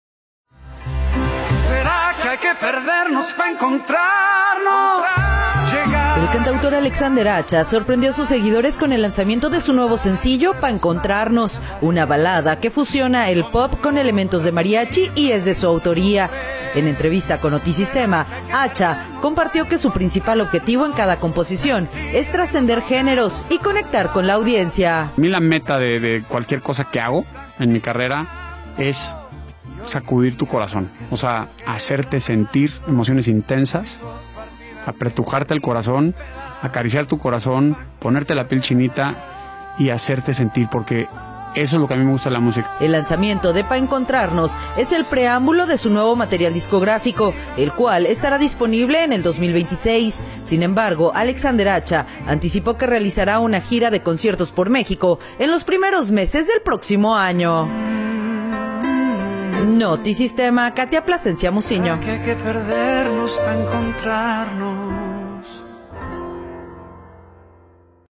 En entrevista con Notisistema